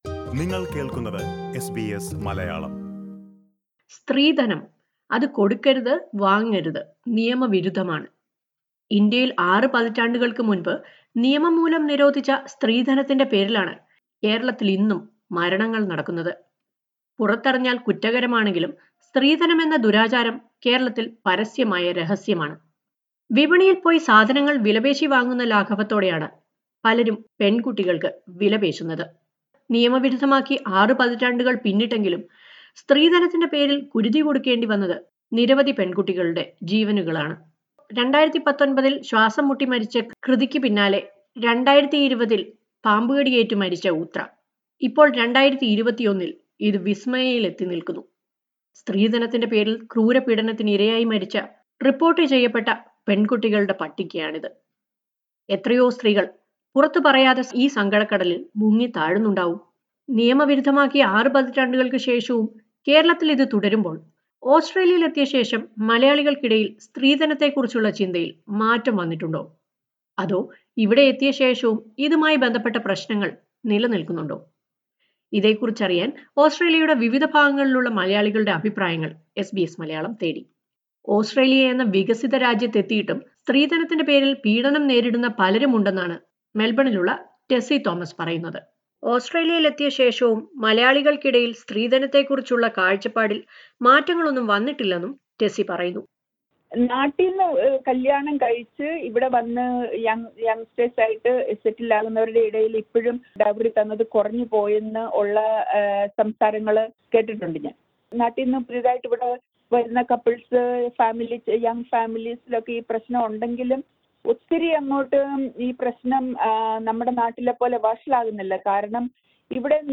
In the wake of the recent dowry deaths that sparked outrage from Malayalees around the globe, SBS Malayalam spoke to a few Australian Malayalees to know if their opinions on dowry has changed after reaching Australia. Listen to a report on that.